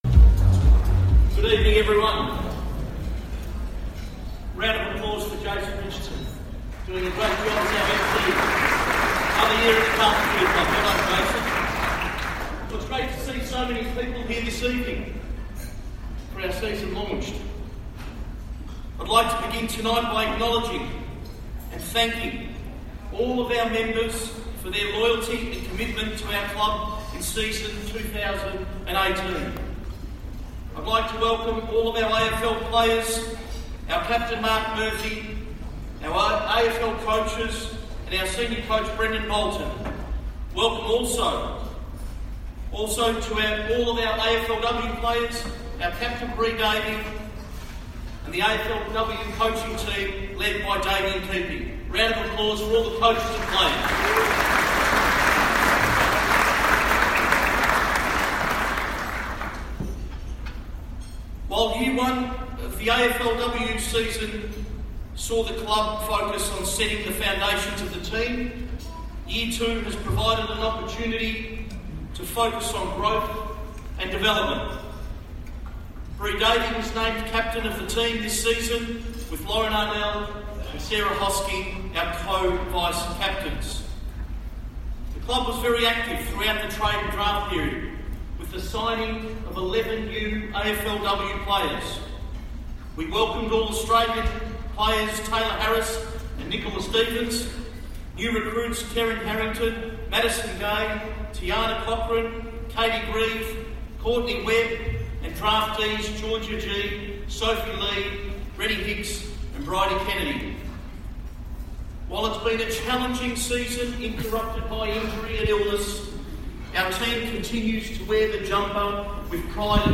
speaks to guests at the Blues' 2018 Season Launched, presented by Hyundai.